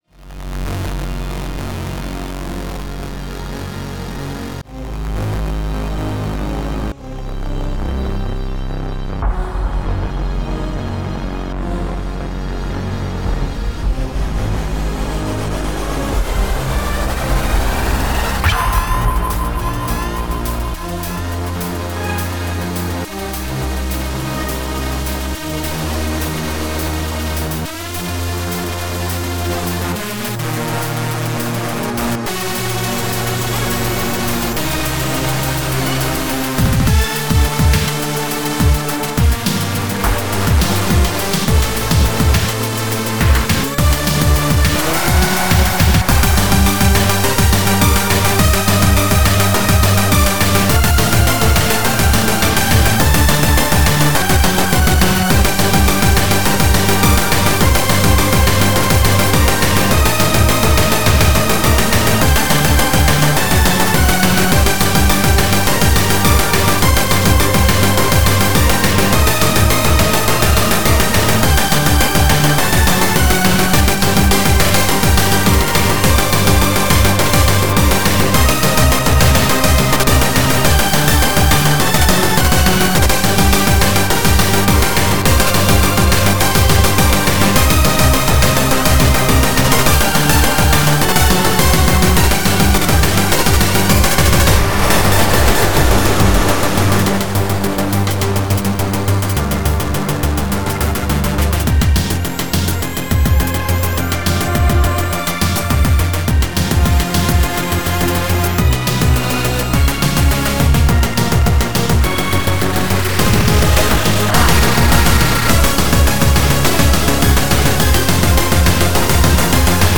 Module music I've composed over the years using OpenMPT.